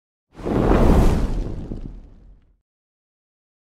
Flames Effect - Botón de sonido
Flames Effect
flames-effect.mp3